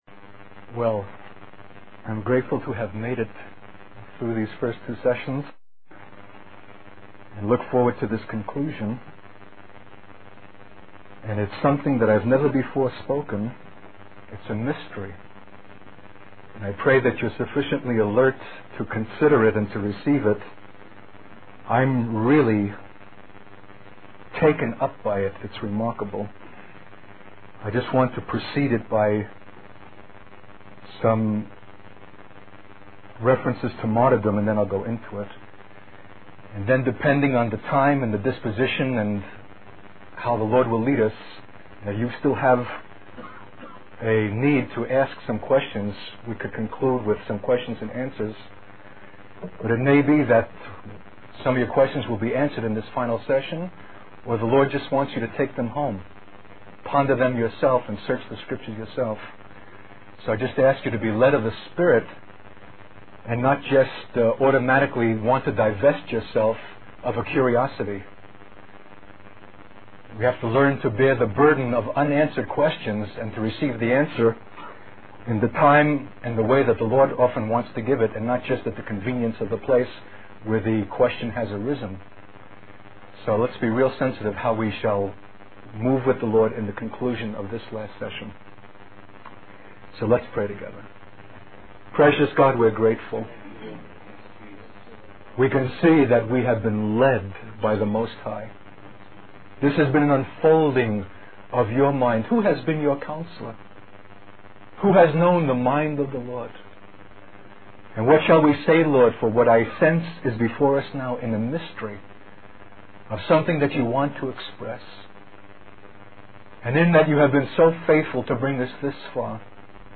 In this sermon, the speaker emphasizes the importance of the church demonstrating the power of forgiveness and meekness in the face of unjust suffering.